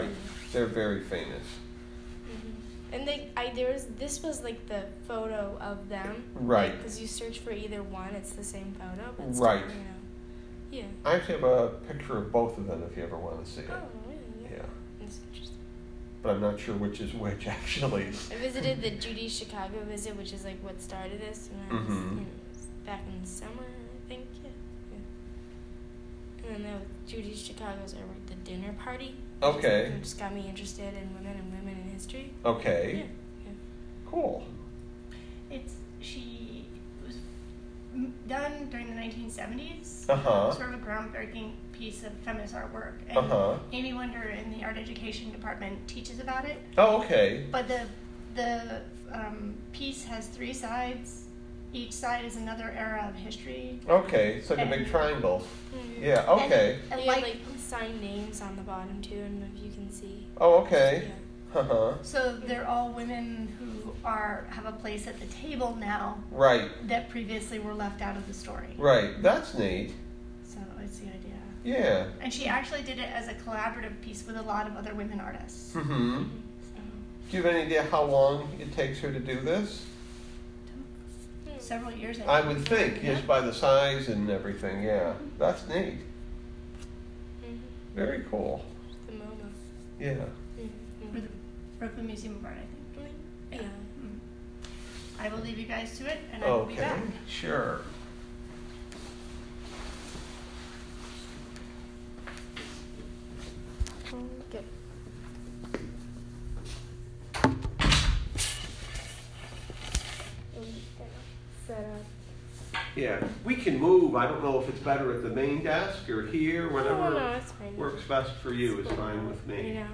Full audio interview